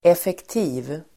Uttal: [efekt'i:v (el. 'ef:-)]